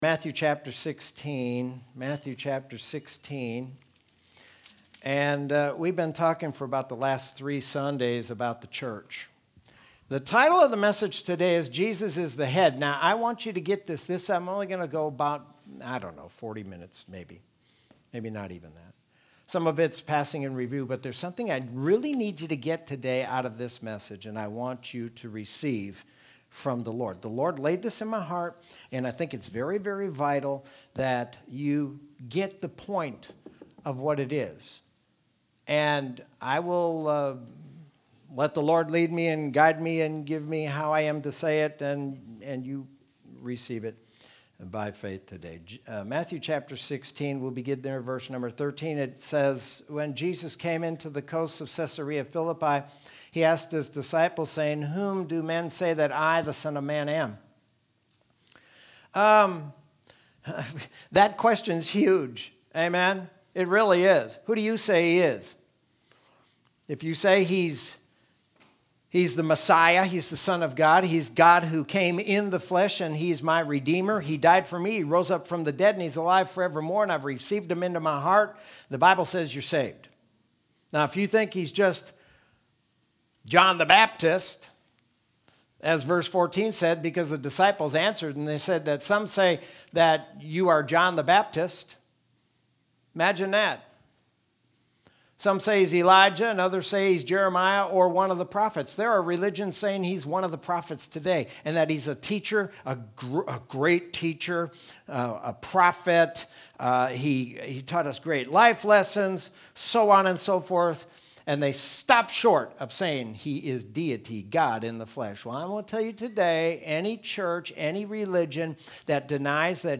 Sermon from Sunday, October 4th, 2020.